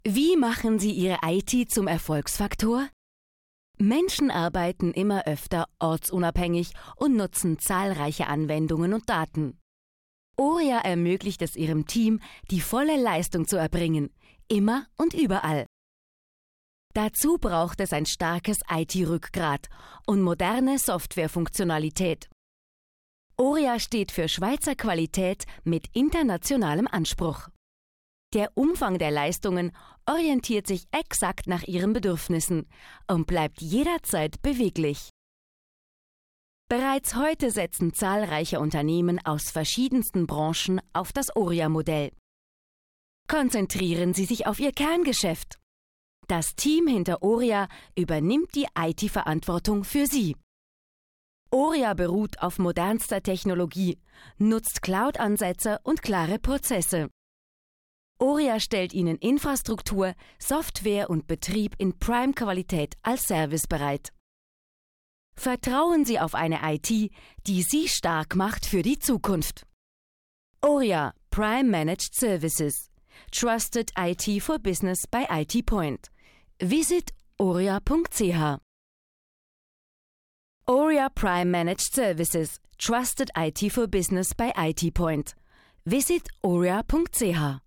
Werbung Demo